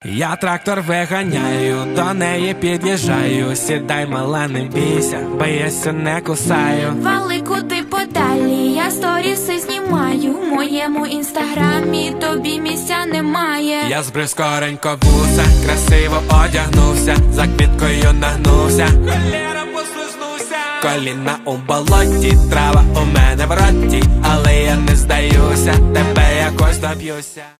Веселая украинская пародия на популярную песню